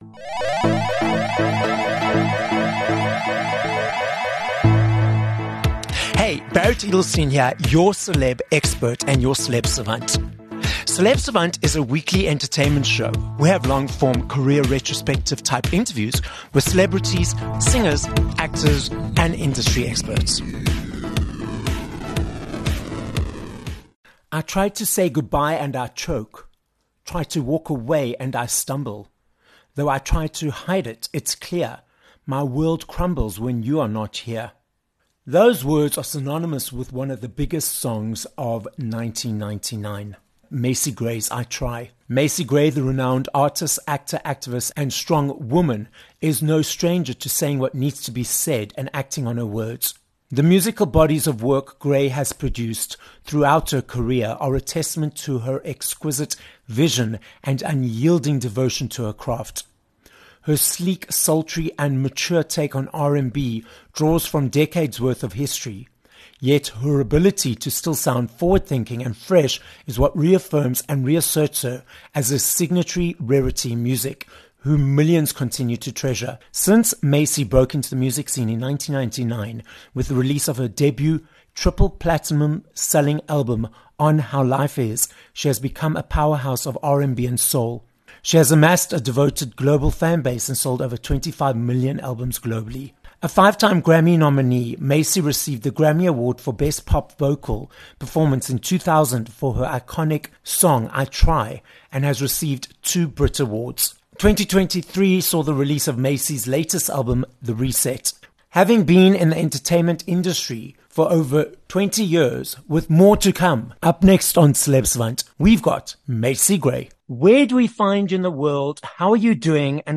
15 Oct Interview with Macy Gray